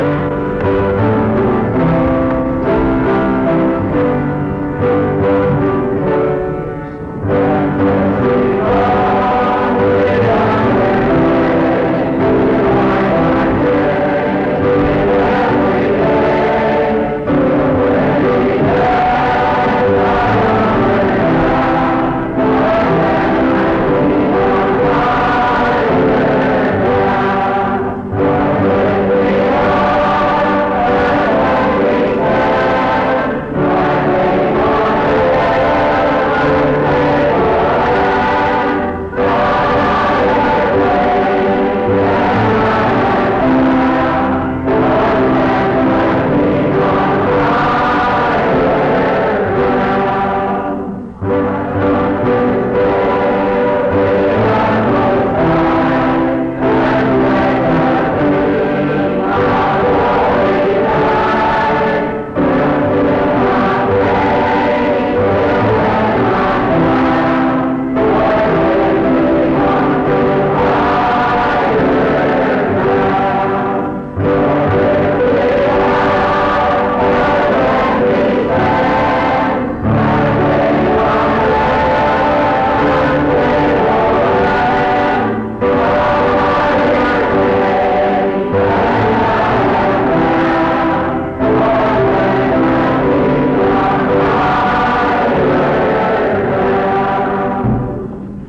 Higher Ground Item c41b88fae7ad1577a235c747a74d9b369c41dfc2.mp3 Title Higher Ground Creator Congregation Description This recording is from the Monongalia Tri-District Sing. Mount Union Methodist Church II, rural, Monongalia County, WV, track 145J.